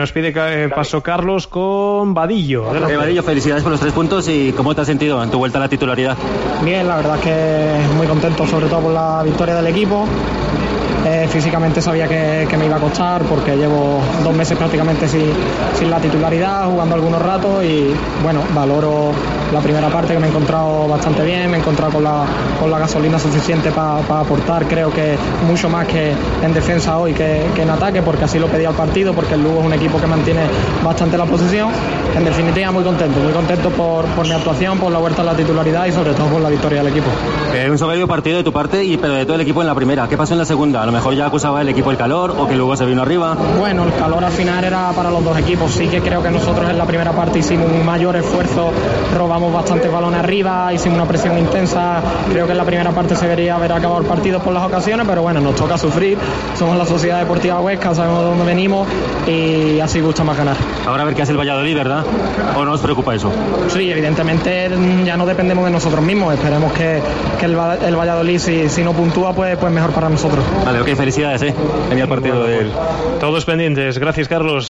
en zona mixta